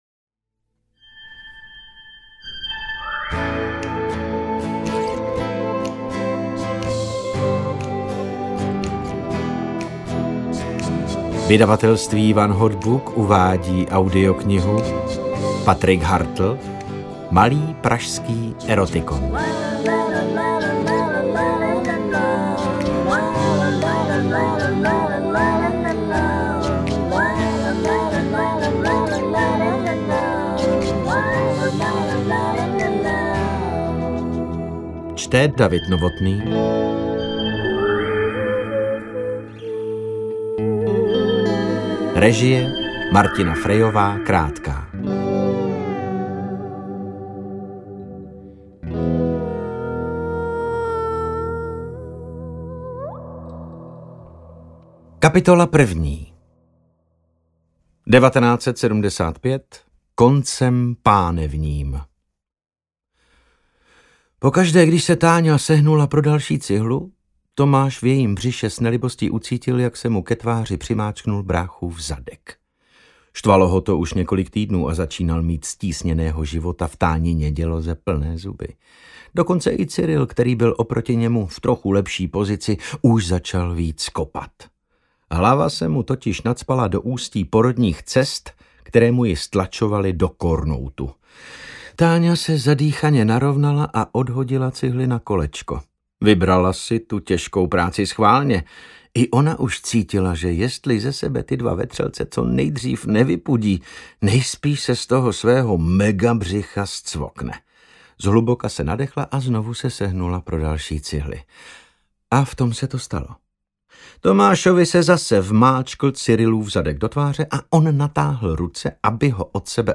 Interpret:  David Novotný
Malý pražský erotikon je audioknížka o lásce určená i těm posluchačům, kteří bývají k příběhům o lásce zpravidla nedůvěřiví. Na Babě stojí nad strání s výhledem na Prahu dvě sousedící řadovky, ve kterých bydlí rodiny, jejichž členové prožívají pod povrchem zdánlivě všedních dní...